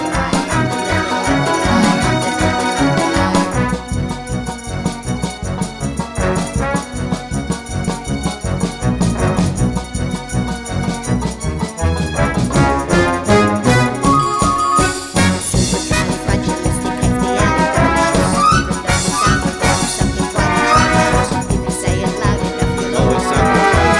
no Backing Vocals Soundtracks 2:03 Buy £1.50